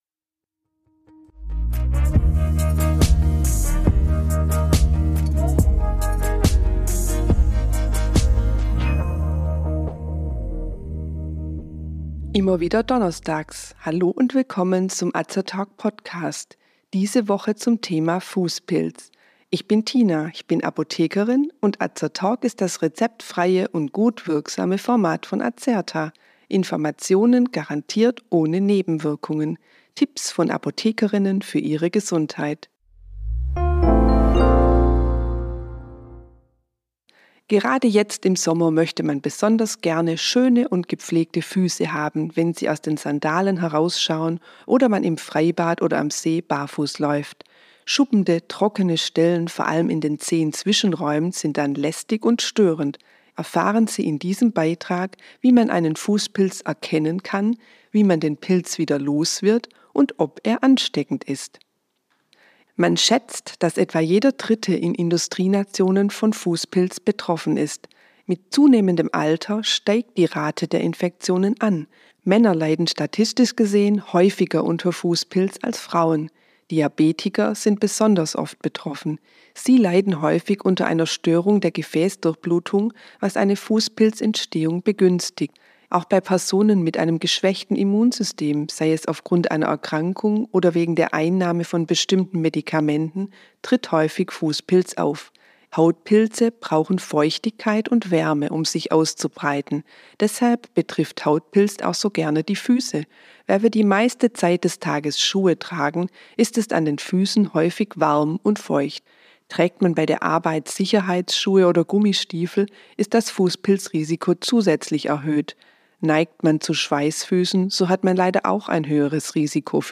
Azertalk: Von Apothekerinnen für Ihre Gesundheit.